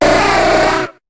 Cri d'Otaria dans Pokémon HOME.